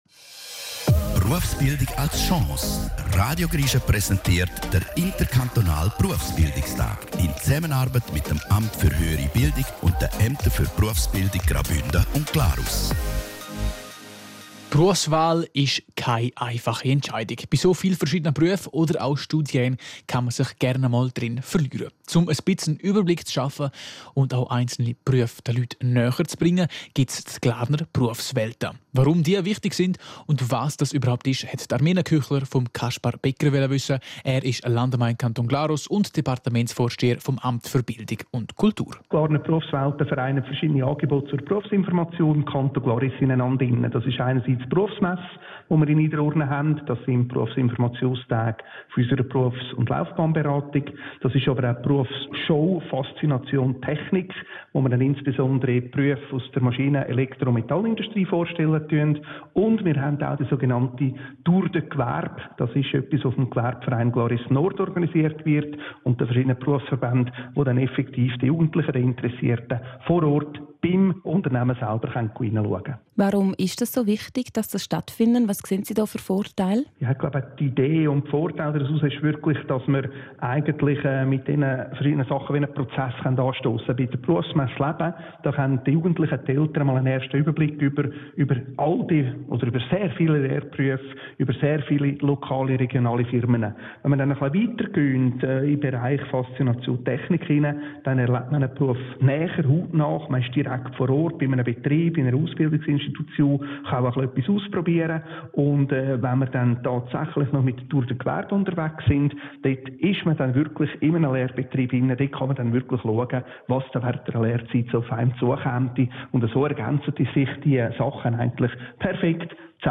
Zusammen mit Radio Grischa stehen folgende Themen im Fokus:
Kaspar Becker, Landammann Kanton Glarus, Departementsvorsteher Bildung und Kultur
Mittschnitt Themenwoche - Berufsbildung.MP3